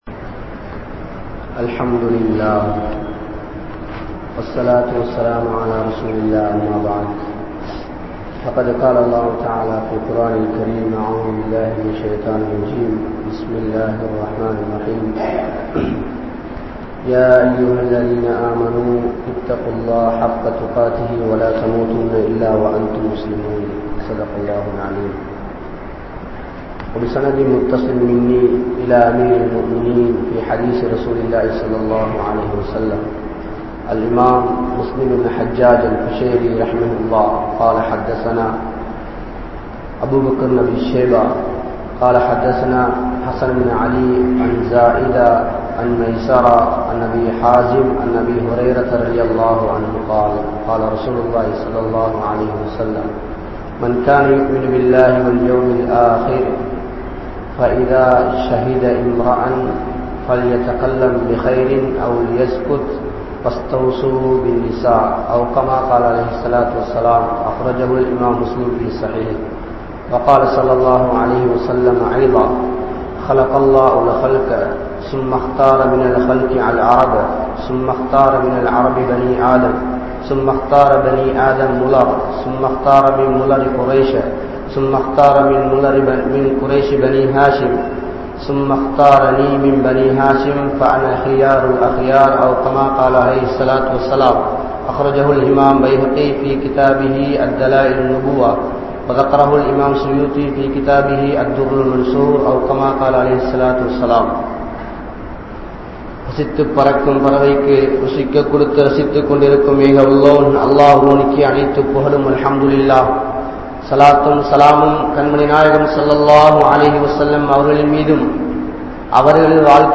Kudumpathin Poruppuhal (குடும்பத்தின் பொறுப்புகள்) | Audio Bayans | All Ceylon Muslim Youth Community | Addalaichenai
Mallawapitiya Jumua Masjidh